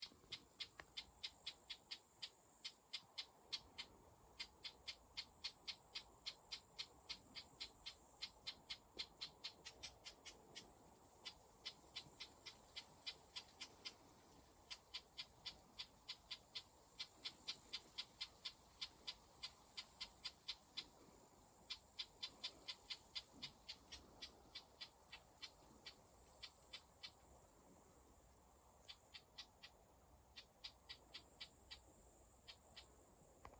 Bird Aves sp., Aves sp.
Administratīvā teritorijaStrenču novads
StatusAgitated behaviour or anxiety calls from adults